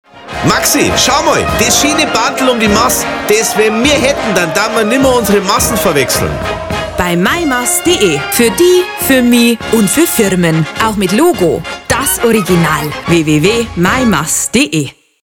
Meimaß_2012_Spot.mp3